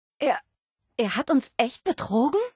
Kategorie:Fallout 76: Audiodialoge Du kannst diese Datei nicht überschreiben.